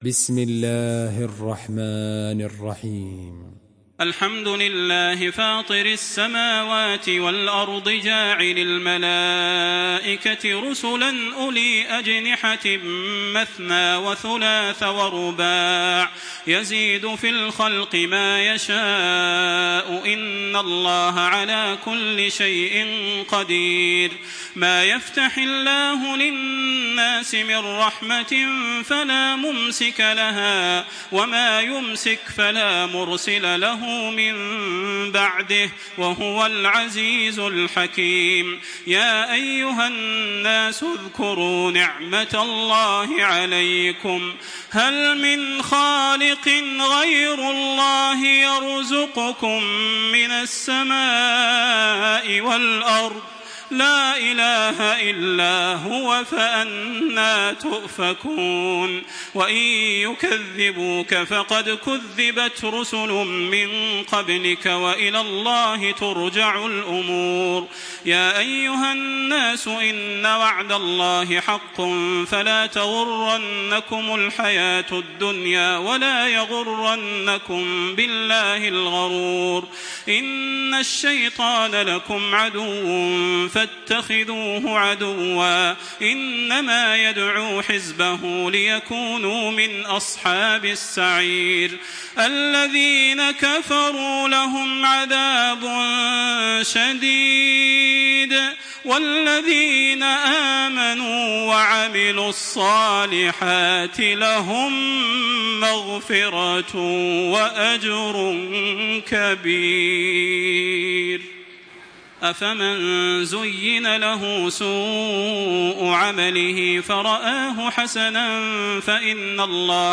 سورة فاطر MP3 بصوت تراويح الحرم المكي 1426 برواية حفص
مرتل